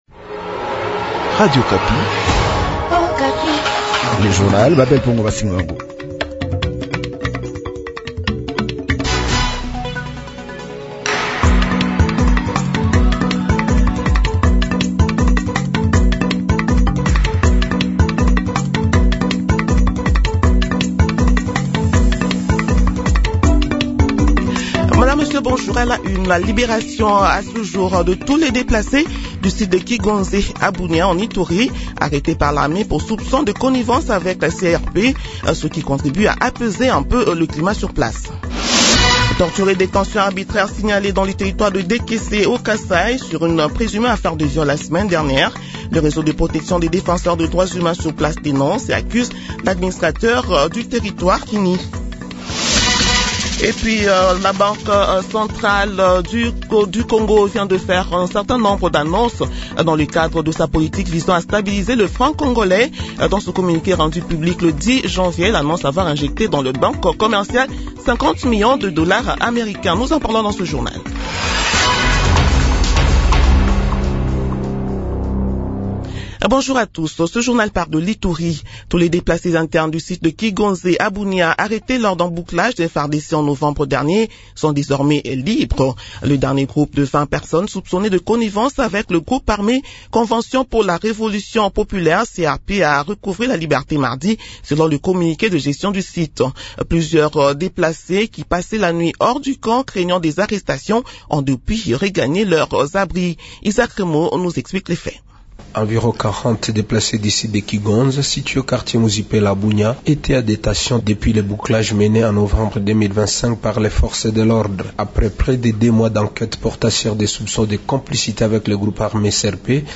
Journal 6 h de ce jeudi 15 janvier 2026